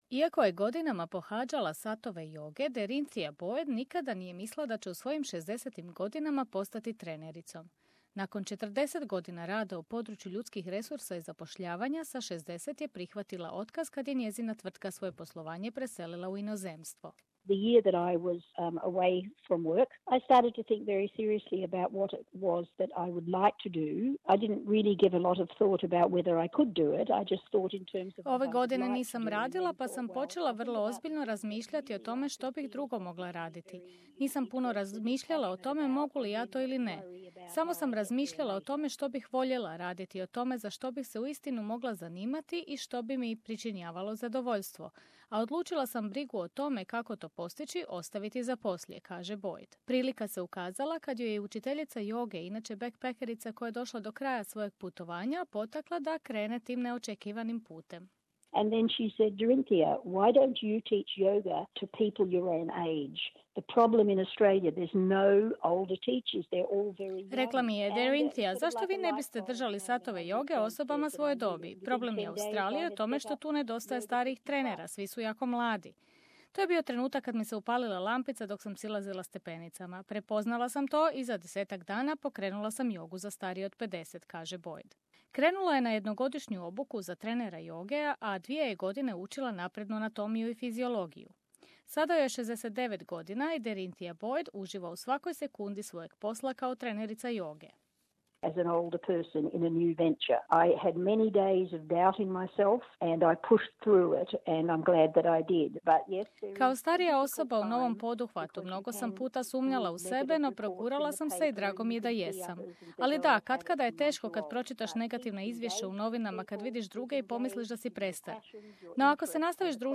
Bez obzira na to tražite li posao, želite li pokrenuti vlastitu tvrtku ili ispuniti svoj dugogodišnji san, započeti novu karijeru u poznijoj životnoj dobi može biti izazovno. U prilogu poslušajte što vam sve može pomoći u uspješnoj promijeni karijere, o čemu smo razgovarali sa ženom koja je u svojim šezdesetim godinama postala trenerica joge, sa životnom trenericom i s osobom koja se bavi zapošljavanjem starijih osoba.